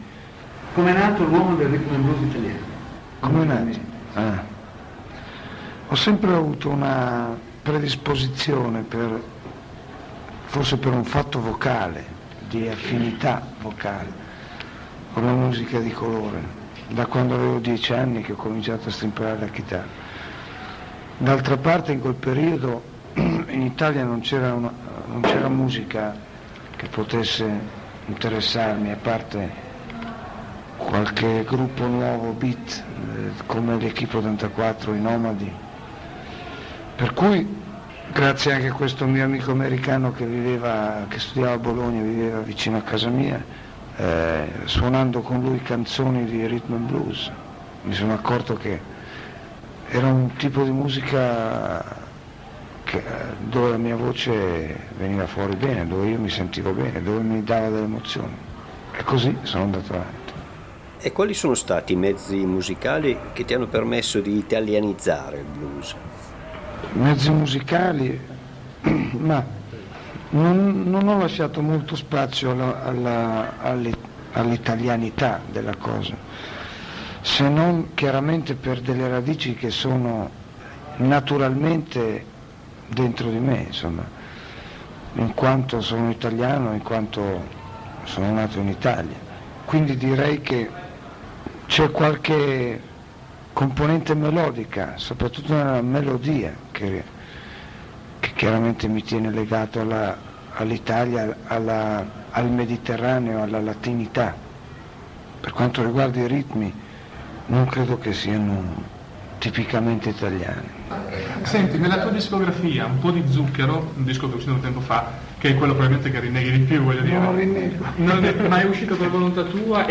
la conferenza stampa prima del concerto